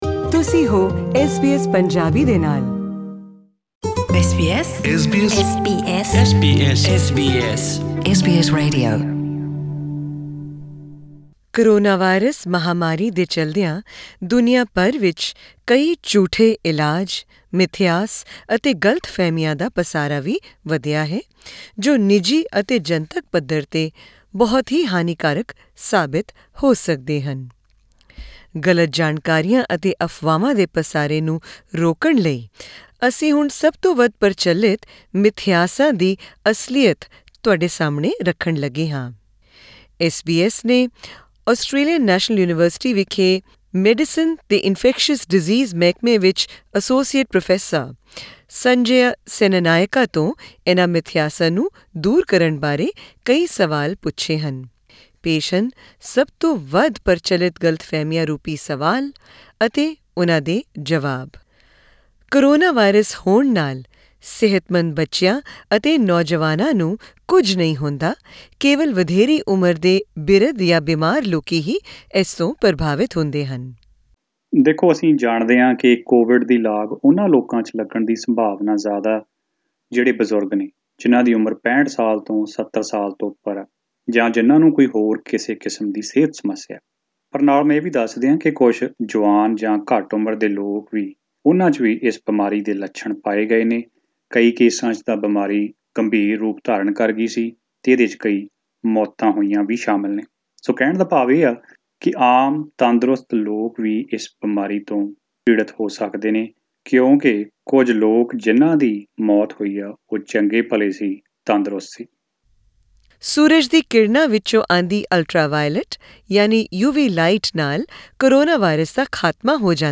hear the myths and questions in the female voice